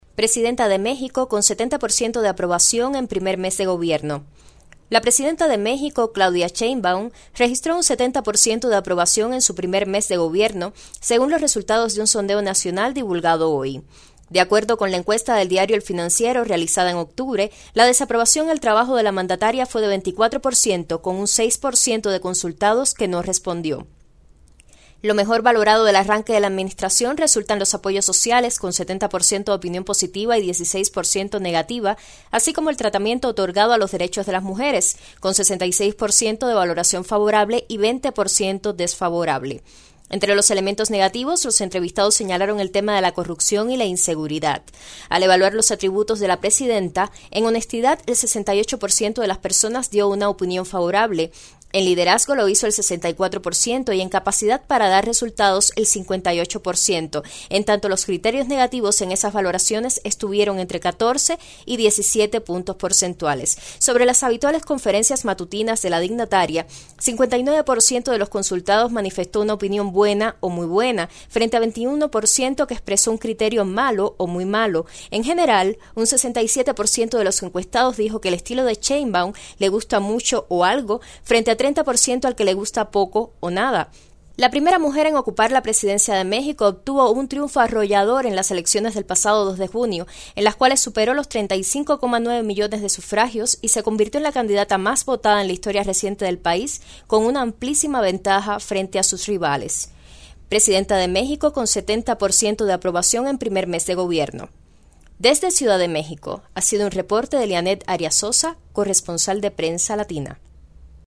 desde Ciudad de México